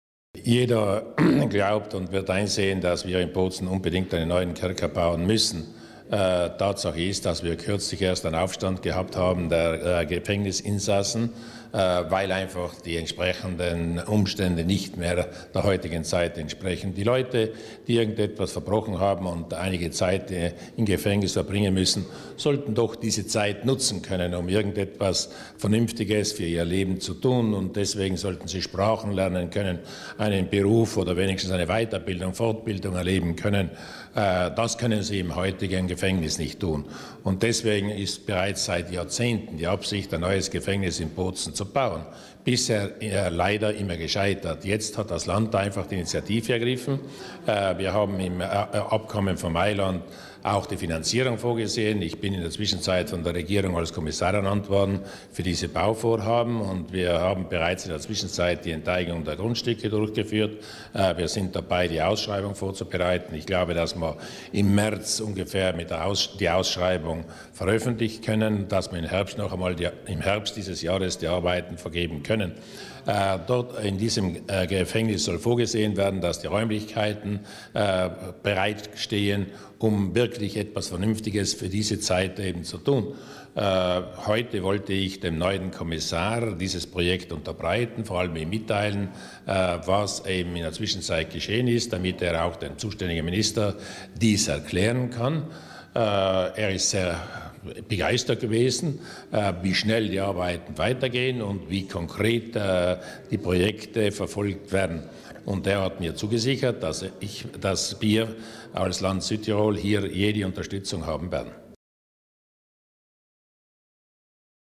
Landeshauptmann Durnwalder zum Ausgang des Treffens mit Präfekt Angelo Sinesio